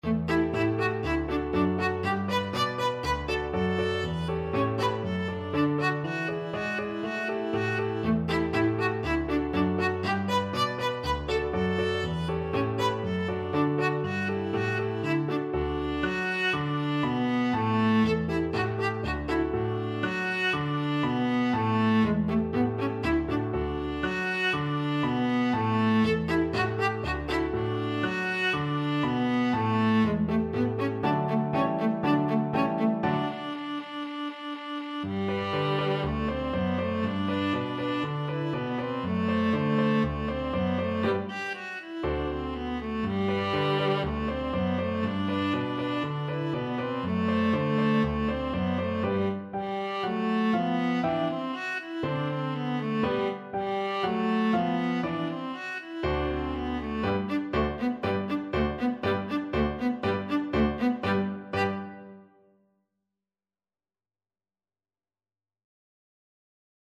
4/4 (View more 4/4 Music)
Allegro = 120 (View more music marked Allegro)
Classical (View more Classical Viola Music)